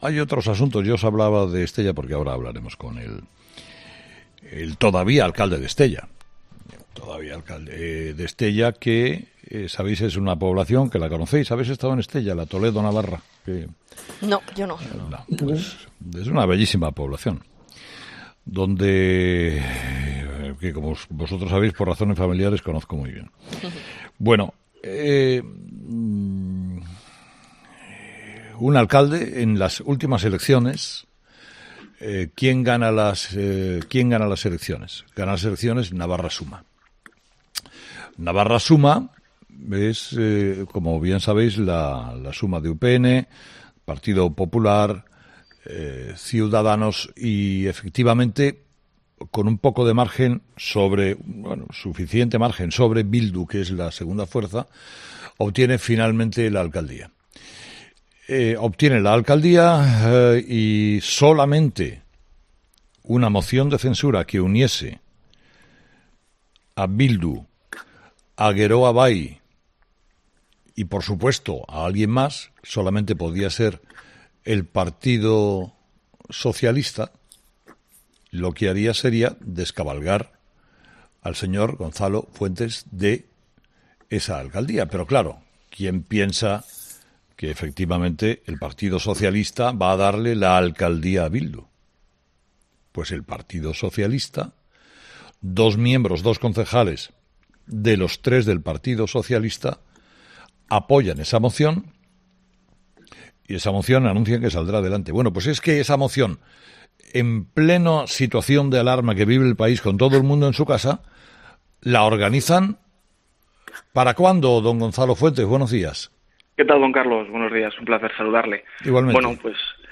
Gonzalo Fuentes, alcalde de Estella por Navarra Suma, la coalición de UPN, PP y Cs, ha sido entrevistado este martes en 'Herrera en COPE' horas antes de que el Ayuntamiento vote la moción de censura que investirá alcalde a Koldo Leoz de EH Bildu con el apoyo de Geroa Bai y dos de los tres concejales del PSN que fueron elegidos en las pasadas elecciones municipales, y que después fueron expulsados del partido.